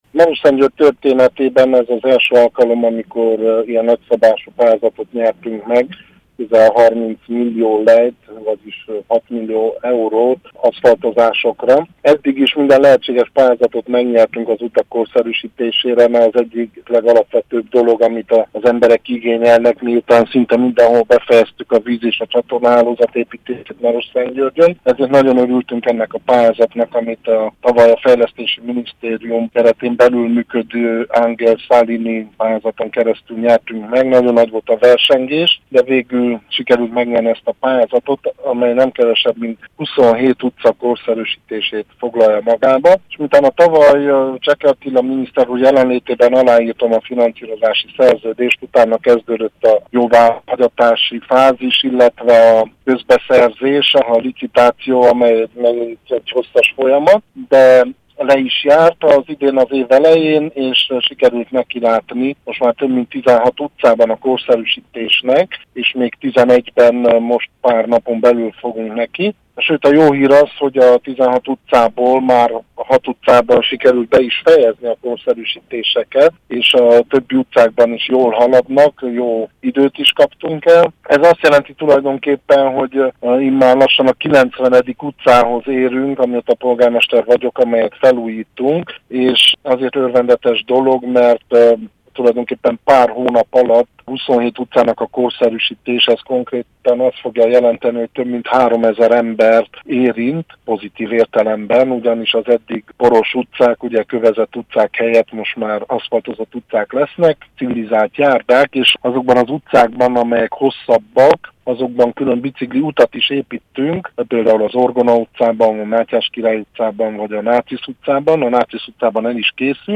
Marosszentgyörgy polgármesterét, Sófalvi Szabolcsot kérdezte